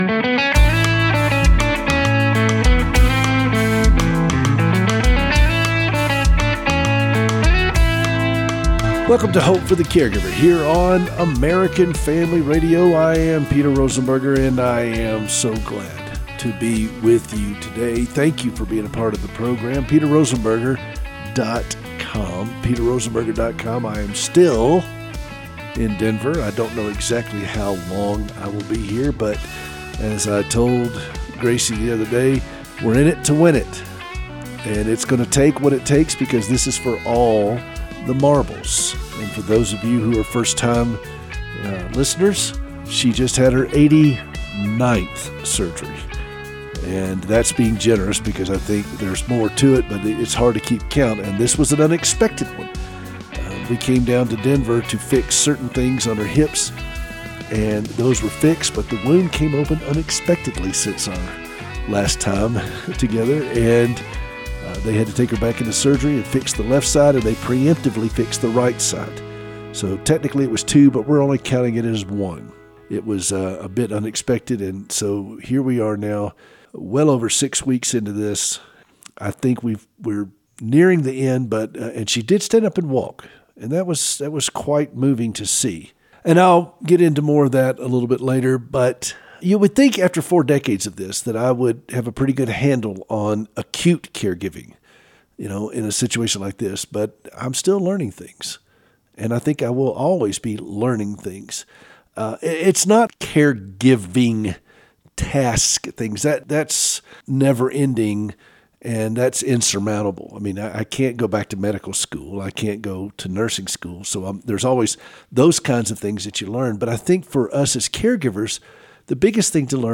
LIVE on Saturday mornings at 7:00AM.